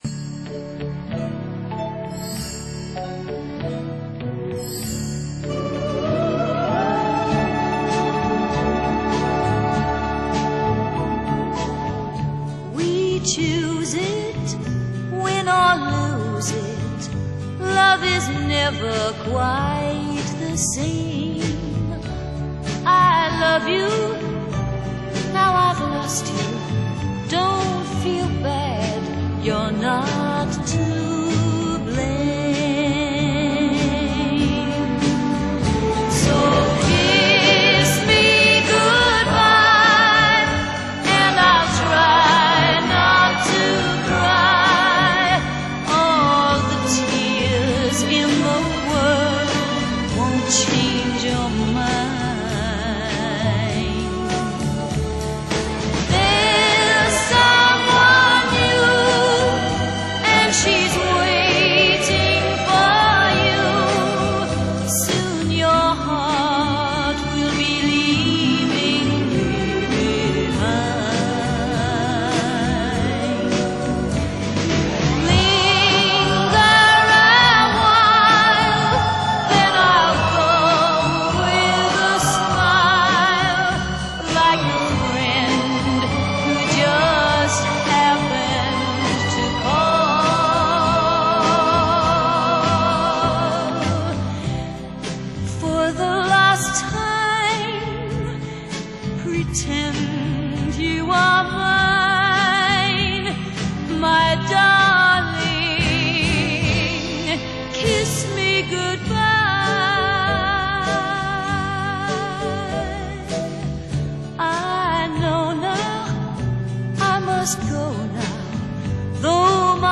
採用著名SADLE重新Mastering，令音樂得到前所未有的效果。